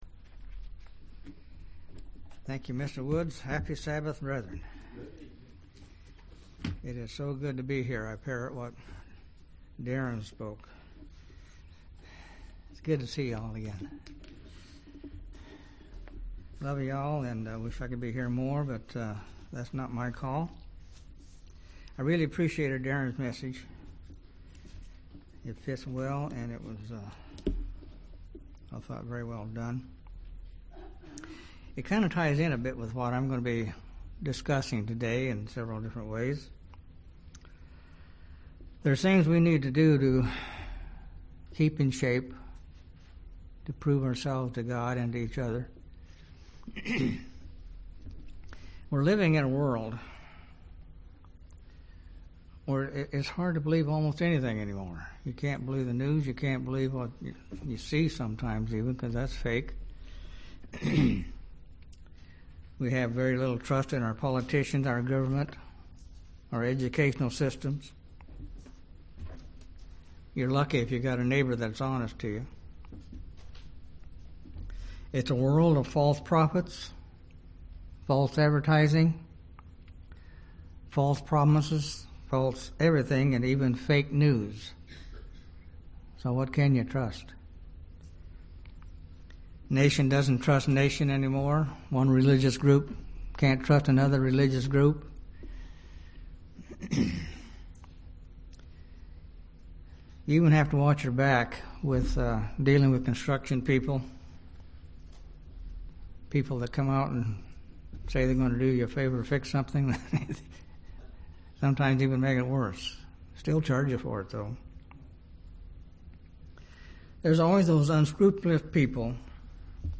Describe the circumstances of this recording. Given in Gadsden, AL